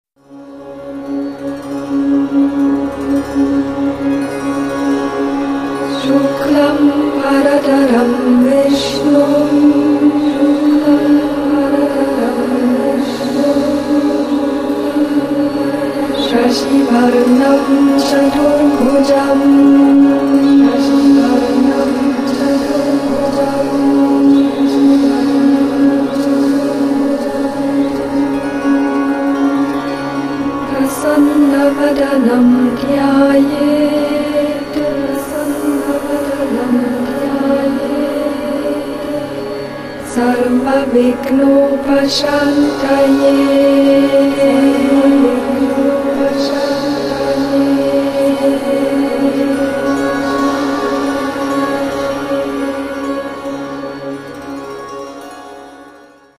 Sublime & spacious vocal chants to the Divine Mother - Devi.